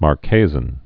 (mär-kāzən, -sən)